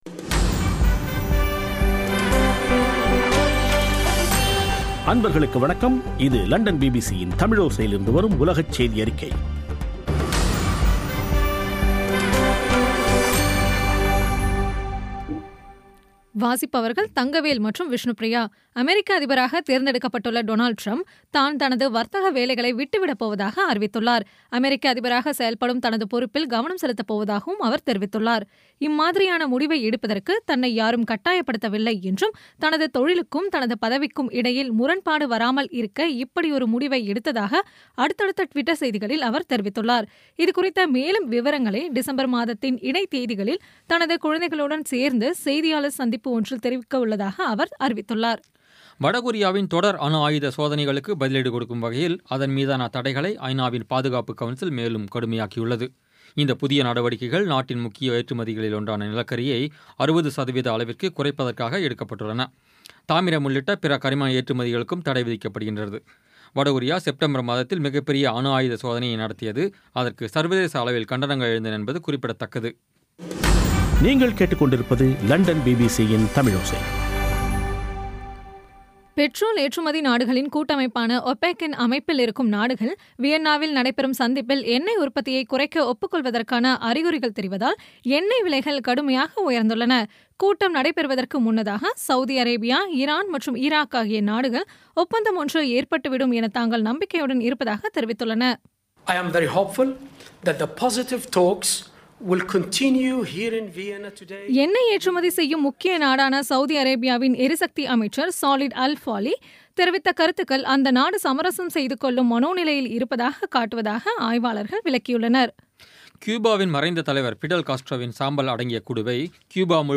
பி பி சி தமிழோசை செய்தியறிக்கை (30/11/16)